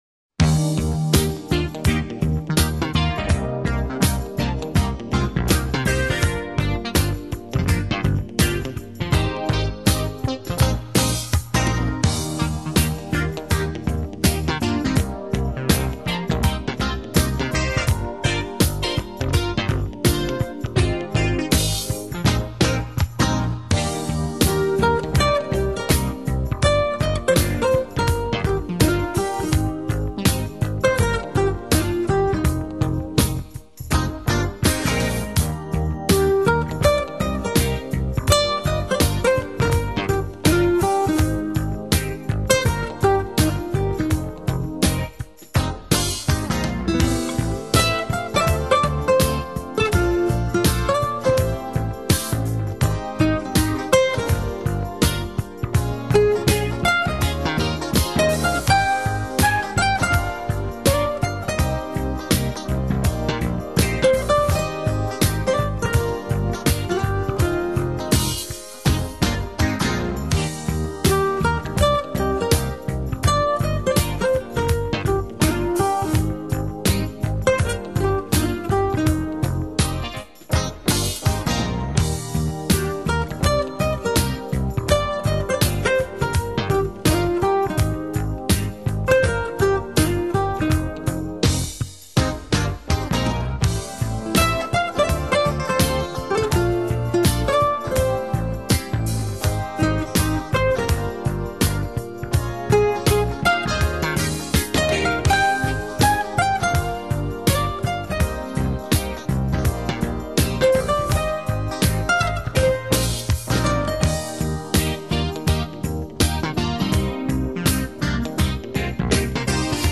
风格类型：爵士蓝调【Jazz&Blues】